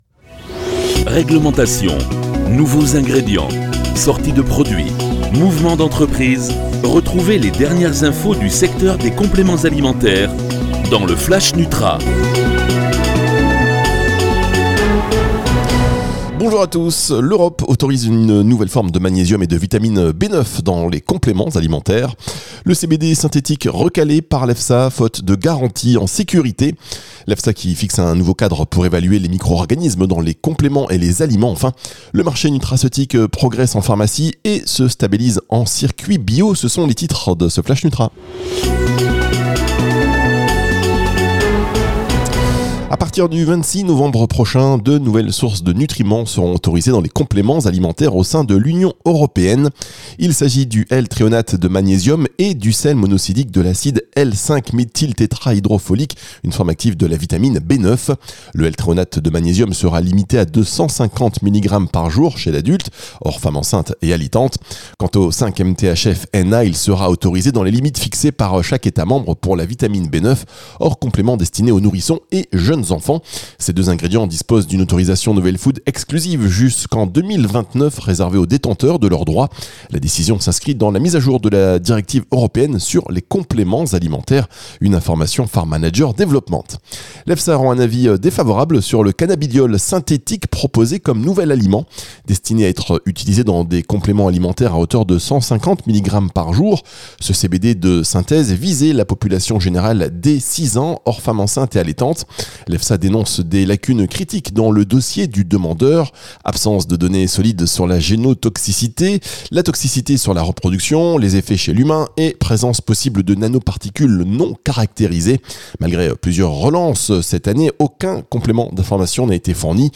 Flash nutra